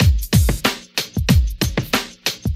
• 123 Bpm '90s Rap Drum Beat D# Key.wav
Free drum loop - kick tuned to the D# note. Loudest frequency: 2071Hz